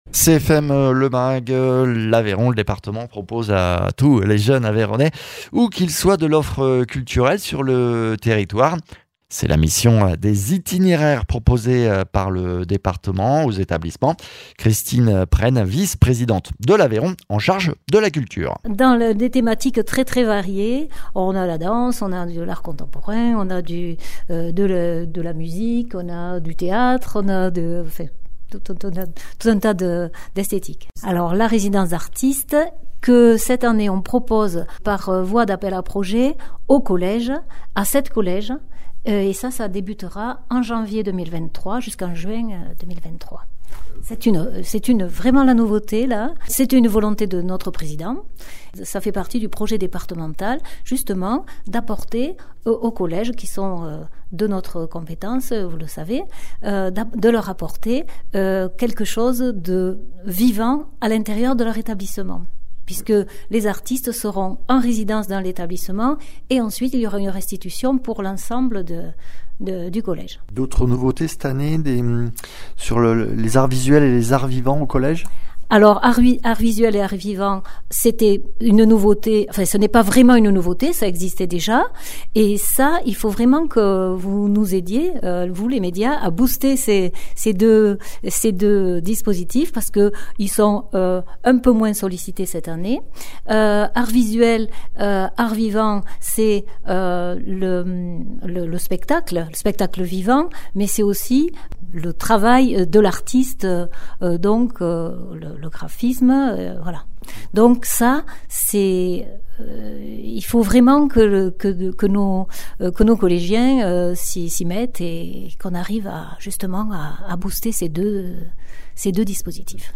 Interviews
Invité(s) : Christine Presne, vice-Présidente du conseil départemental en charge de la culture